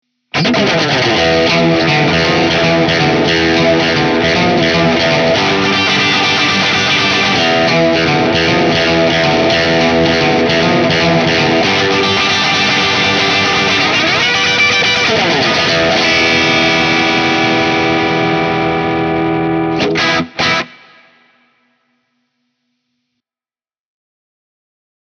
Tutte le clip audio sono state registrate con amplificatore Fender Deluxe e una cassa 2×12 con altoparlanti Celestion Creamback 75.
Clip 4 – Stratocaster, Over Drive as Boost, amp on breakup
Chitarra: Fender Stratocaster (pickup al ponte)
Strat-Boost.mp3